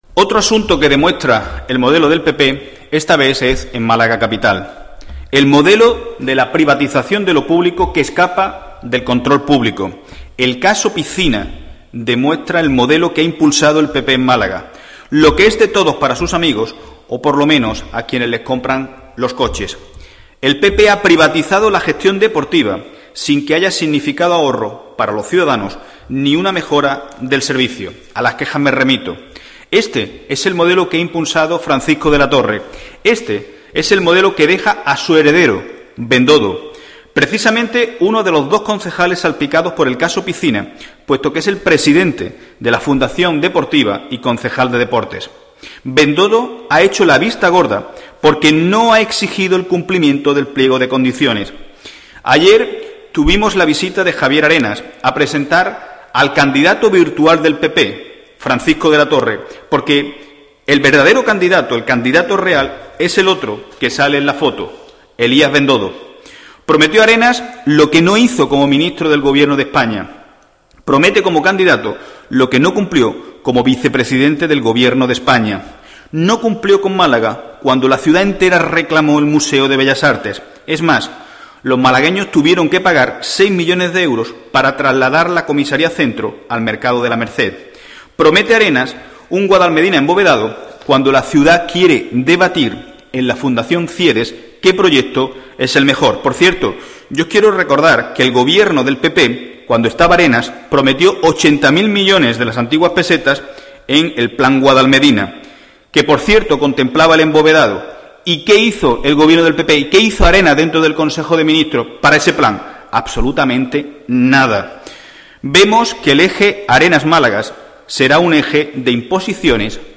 El secretario general del PSOE malagueño, Miguel Ángel Heredia, ha asegurado hoy en rueda de prensa que el presidente del PP andaluz, Javier Arenas, vino ayer a presentar al "candidato virtual" del PP en la capital, Francisco de la Torre, "porque su verdadero candidato es el otro que sale en la foto, Bendodo".